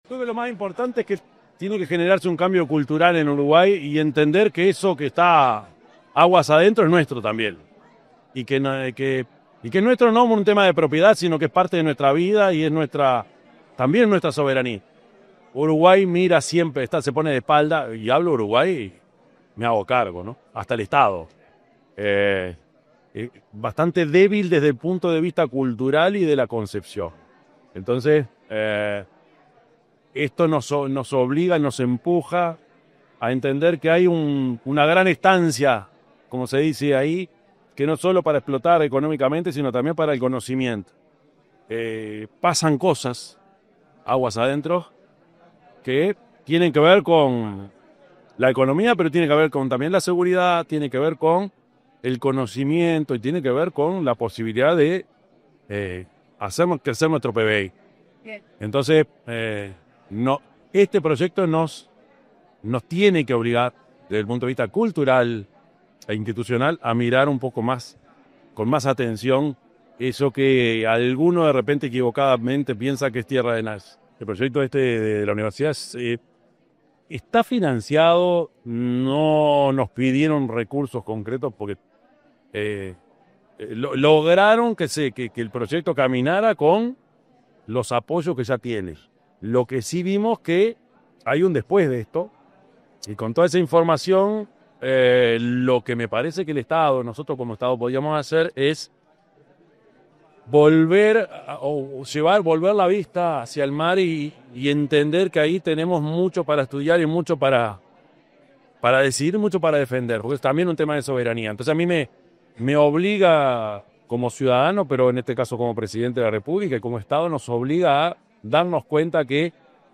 Declaraciones del presidente de la República, Yamandú Orsi